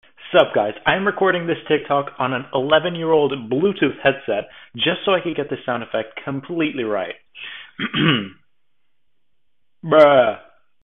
Impressions pt.6 Bruh sound sound effects free download
Impressions pt.6 - Bruh sound effect 2